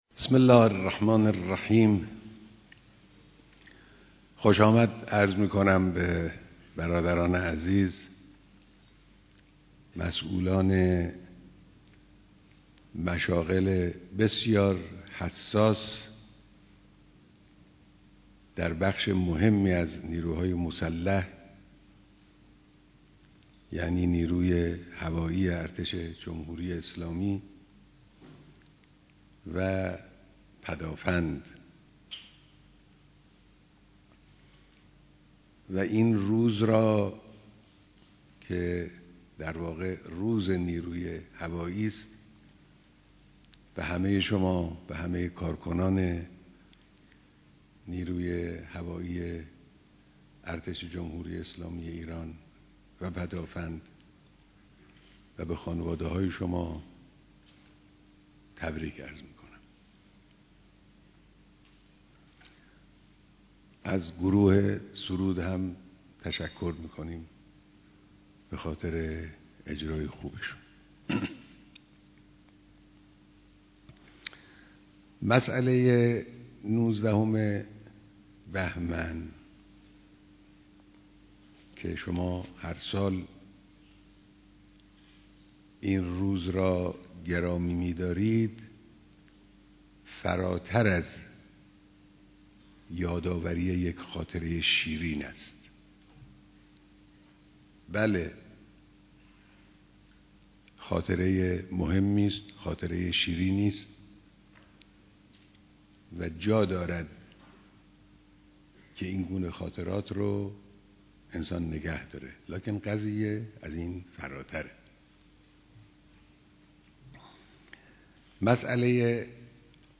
بیانات در دیدار فرماندهان و کارکنان نیروی هوایی ارتش و پدافند هوایی